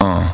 יש סאונדים על רמה, עדכניים ומודרנים. עשיתי איזה דרופ מעניין בליל שישי בהשראת האזעקות(: קחו אוזניות ותכנסו למסע. המסר הוא שבכל זמן עצוב עדיין אפשר לשמוח.